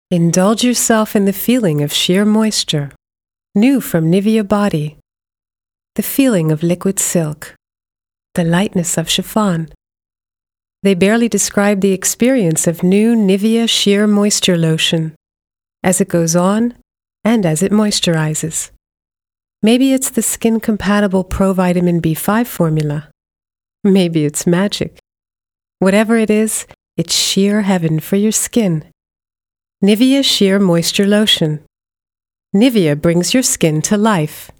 englische Sprecherin, Muttersprache: Englisch (USA) und verschiedene British / Amerikanische Akkzente,
mid-atlantic
Sprechprobe: Sonstiges (Muttersprache):
english voice over artist (us)